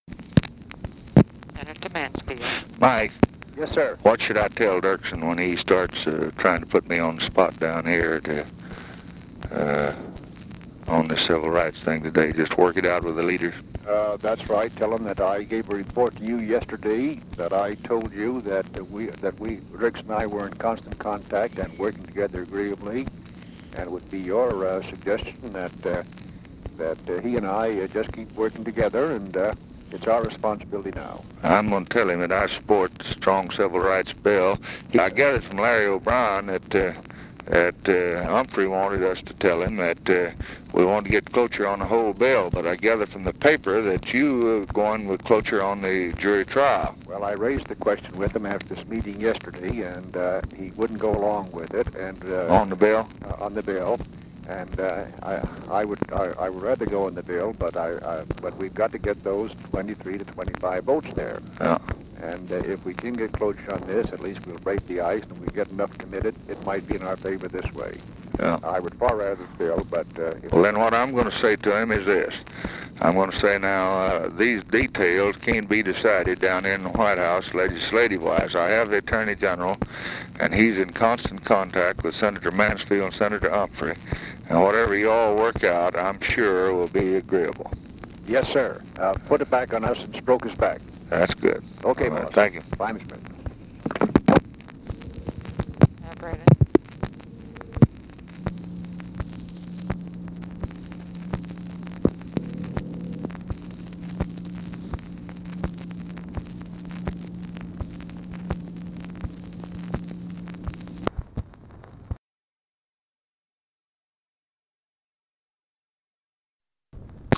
President Lyndon B. Johnson's conversation with Mike Mansfield on Apr 29, 1964.
credit: Lyndon B. Johnson Presidential Recordings